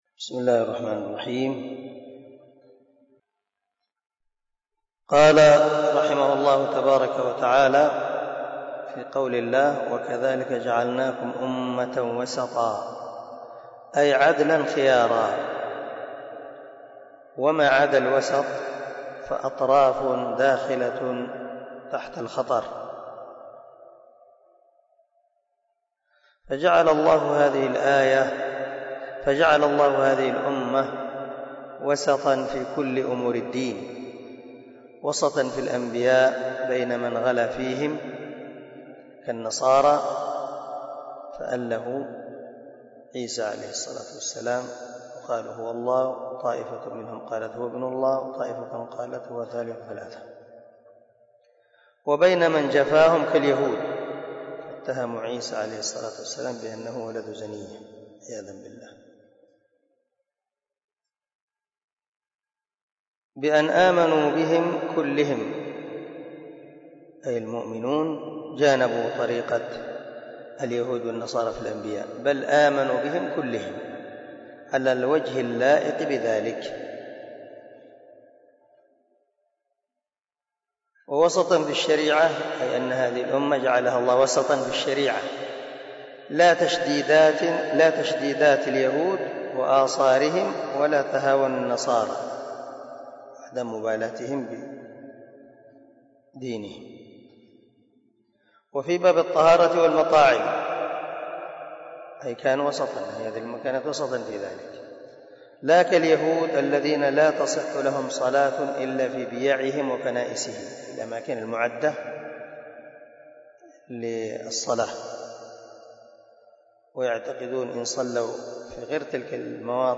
060الدرس 50 تفسير آية ( 143 ) من سورة البقرة من تفسير القران الكريم مع قراءة لتفسير السعدي